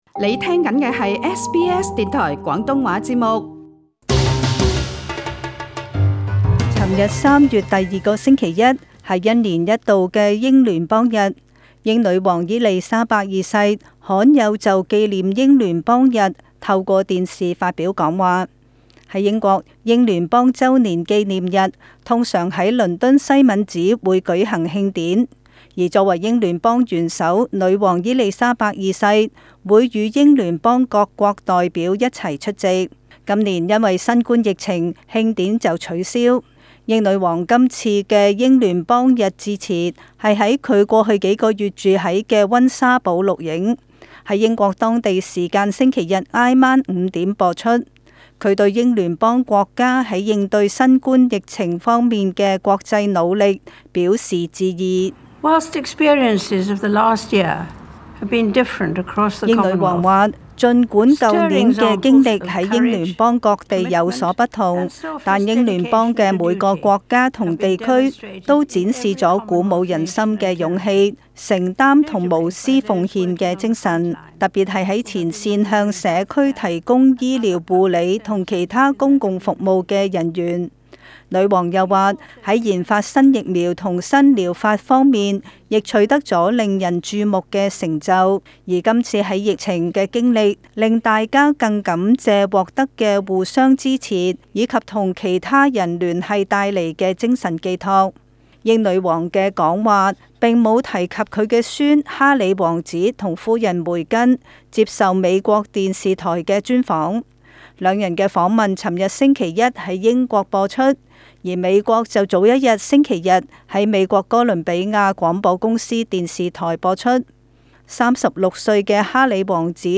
【時事報道】